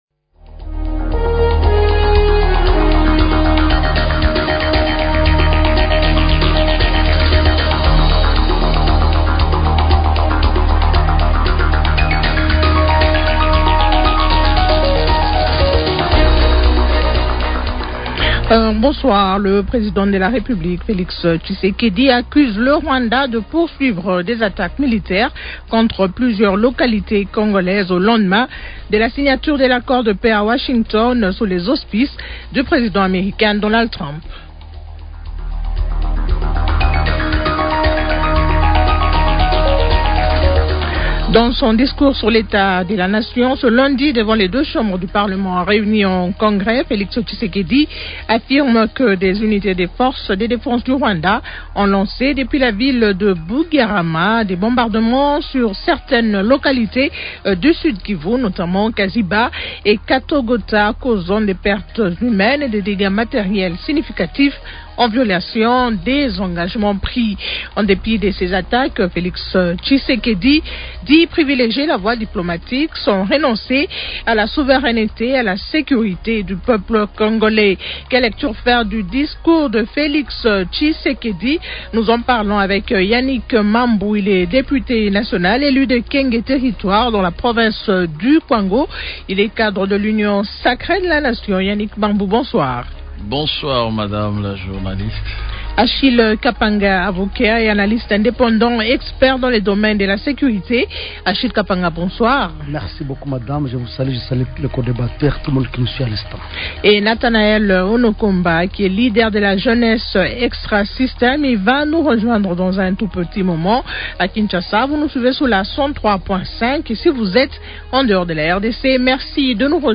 Quelle lecture faire du discours de Felix Tshisekedi ? Invités Yannick Mambu, député nationale élu du territoire de Kenge, dans la province du Kwango